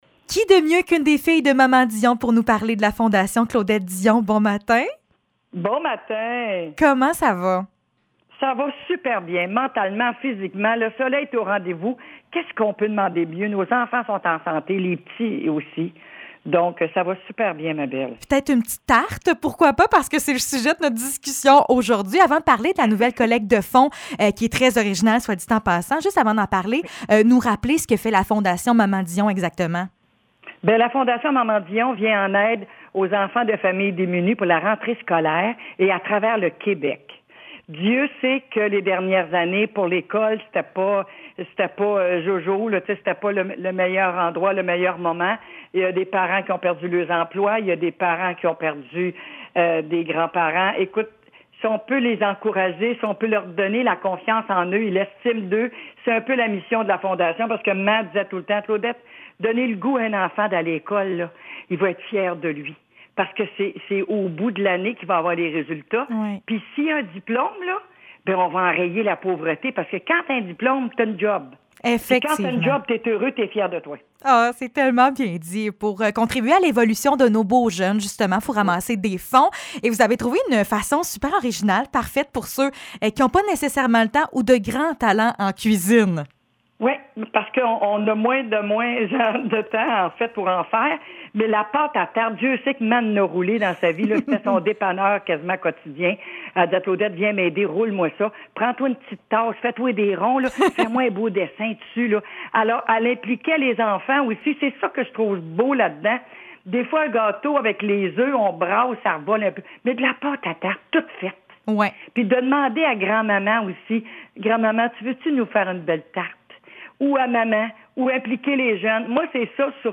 Entrevue avec Claudette Dion (11 mai 2022)